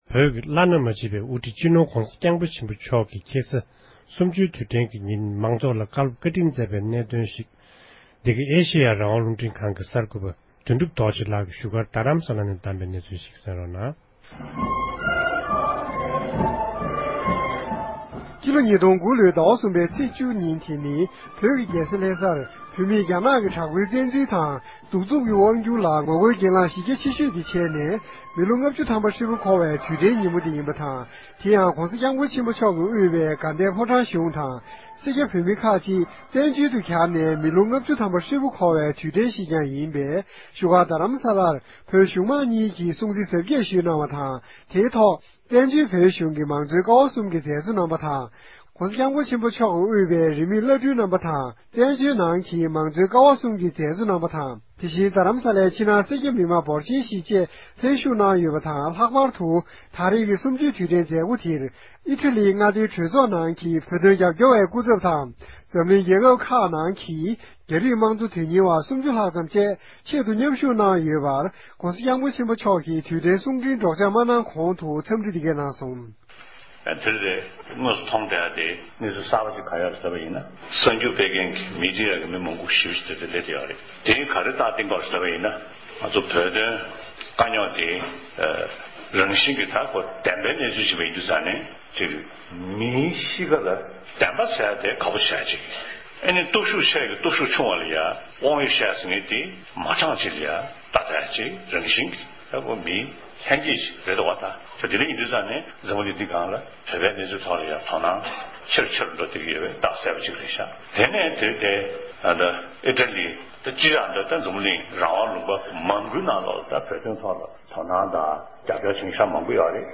༄༅༎ཕྱི་ལོ་ཉིས་སྟོང་དགུ་ཟླ་བ་གསུམ་པའི་ཚེས་བཅུའི་ཉིན་འཛམ་གླིང་ཡོངས་སུ་བོད་མི་གནས་སྡོད་ས་ཁུལ་ཁག་གི་ནང་བོད་མི་དང་བོད་དོན་རྒྱབ་སྐྱོར་བ་རྣམས་ནས་རྒྱ་ནག་དམར་པོའི་གཞུང་གིས་བོད་ནང་བཙན་འཛུལ་བྱས་པར་བོད་མིས་རང་དབང་སླར་གསོའི་སྒེར་ལངས་ཀྱི་ལས་འགུལ་སྤེལ་ནས་མི་ལོ་ལྔ་བཅུ་འཁོར་བའི་ཉིན་མོར་དྲན་གསོ་དང་བོད་ནང་རྒྱ་དམག་གི་འོག་འདས་གྲོངས་སུ་ཕྱིན་པའི་བོད་མི་རྣམས་ལ་རྗེས་དྲན་ཞུས་ཡོད་པའི་སྐོར་ཨེ་ཤེ་ཡ་རང་དབང་རླུང་འཕྲིན་ཁང་གི་གསར་འགོད་པ་རྣམས་ཀྱི་གནས་ཚུལ་ཕྱོགས་བསྒྲིགས་ཞུས་པར་རིམ་བཞིན་གསན་རོགས་ཞུ༎